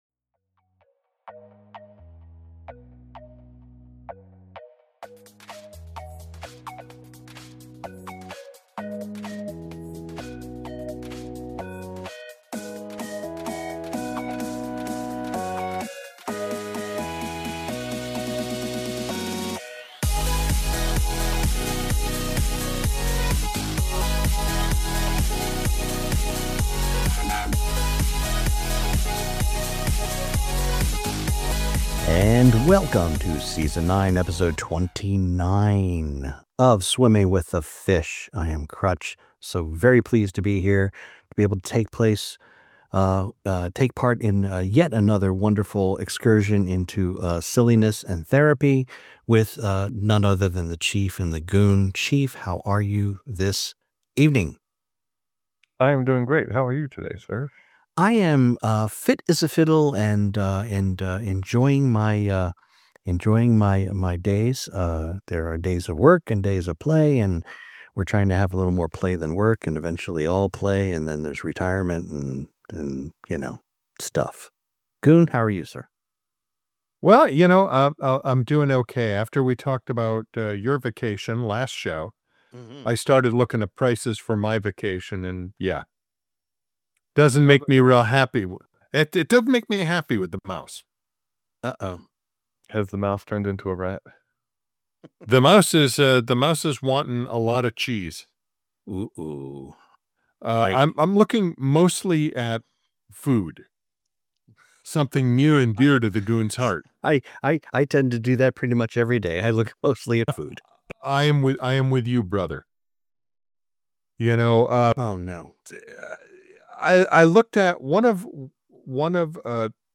Three guys who like to get together weekly and discuss current events, politics, conspiracy theories and stuff that makes us laugh